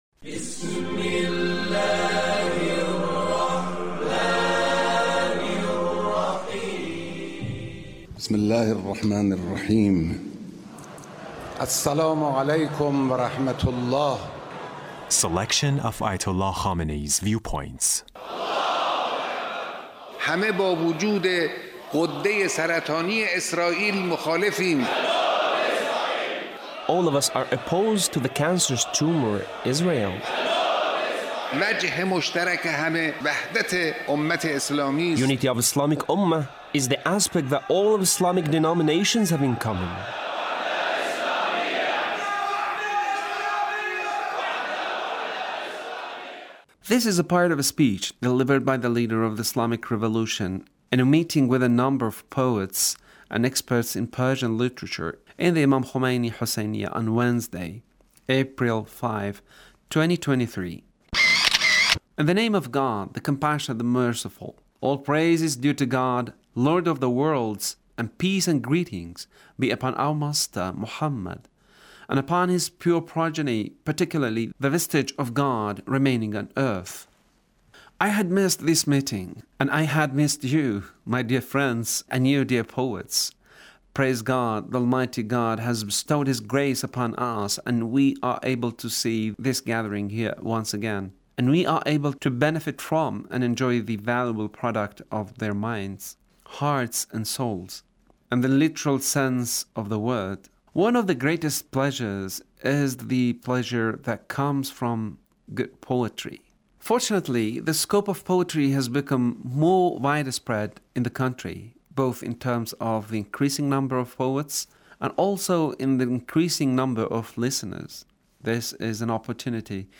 Leader's Speech on Poet's Gathering